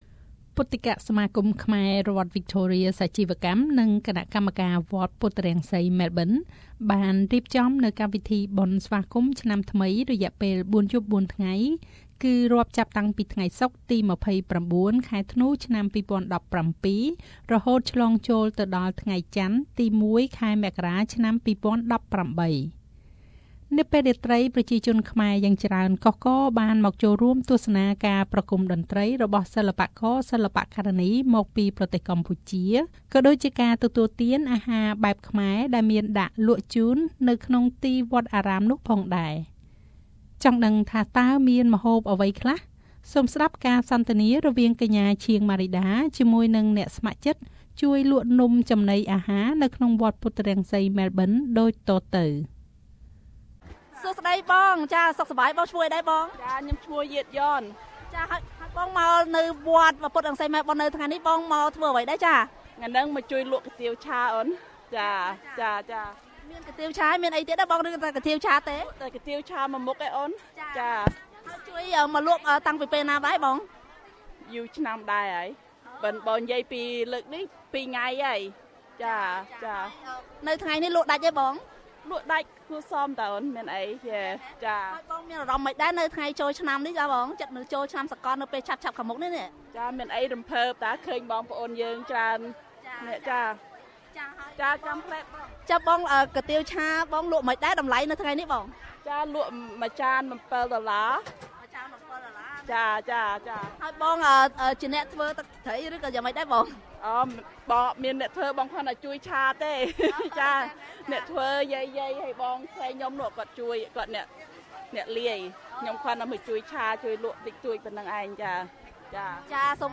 2018 New Year celebration at Wat Puthrangsey Melbourne Source: SBS Khmer